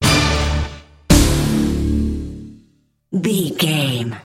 Aeolian/Minor
synthesiser
drum machine
Eurodance